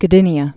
(gə-dĭnē-ə, -dĭnyə)